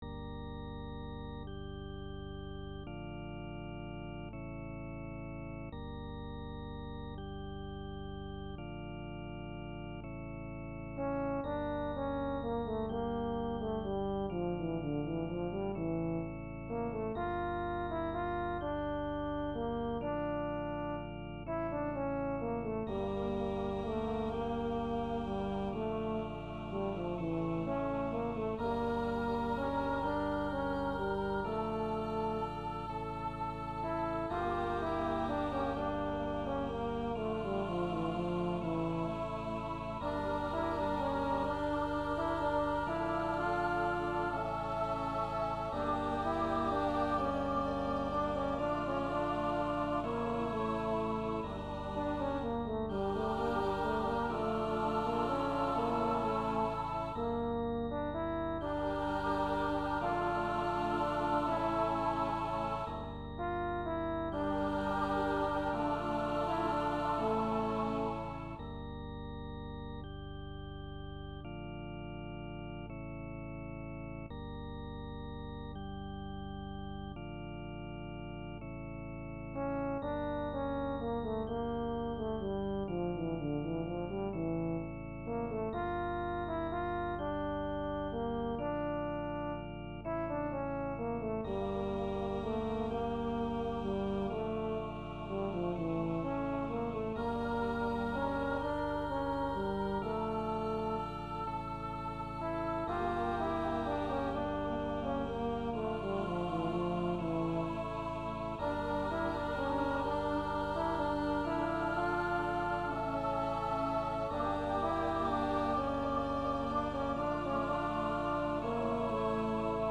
SATB, Trombone
Voicing/Instrumentation: SATB , Trombone